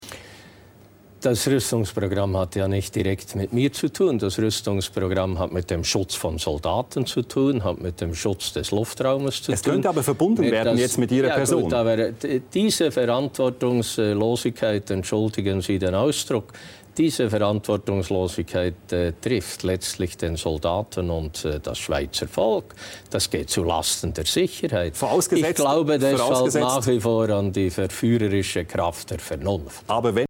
Der Mann, der unbedingt im Amt bleiben muss, sagte heute in der Tagesschau, woran er nach wie vor glaubt: an